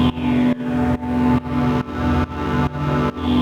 Index of /musicradar/sidechained-samples/140bpm
GnS_Pad-MiscB1:4_140-C.wav